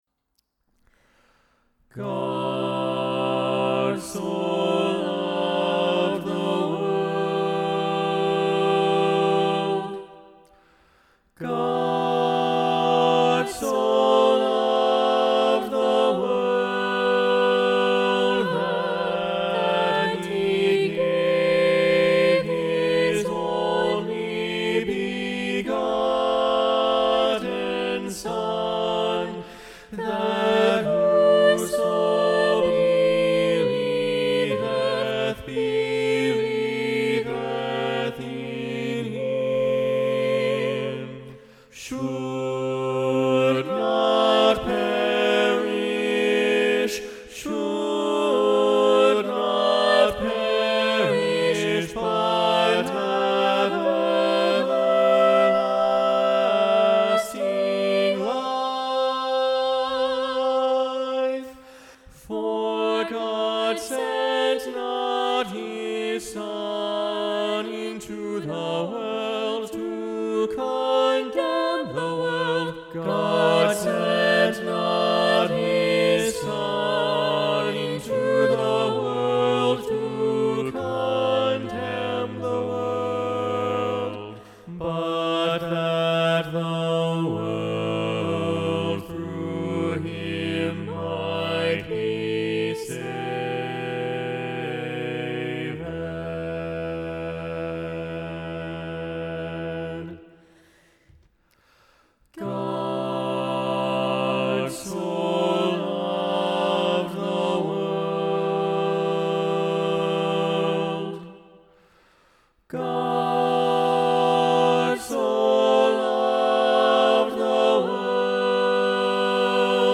God so Loved the World SATB – Alto Muted – John StainerDownload